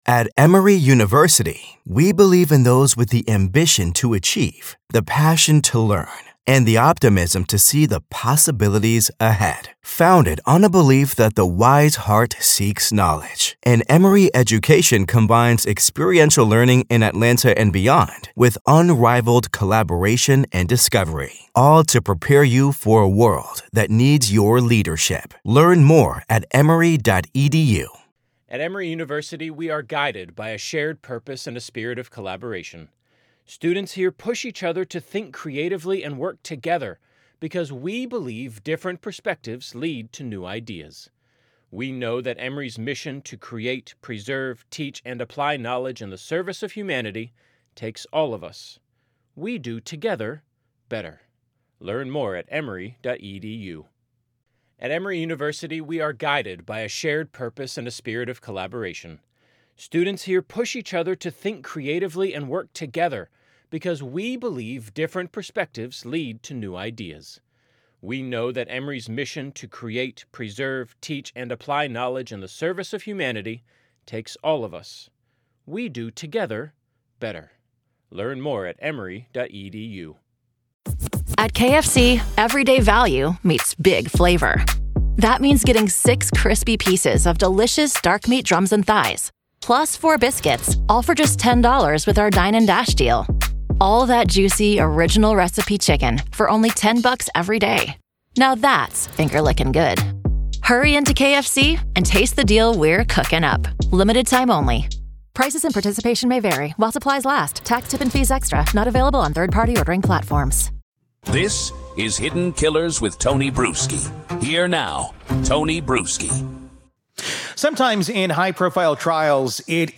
In this conversation, psychotherapist and author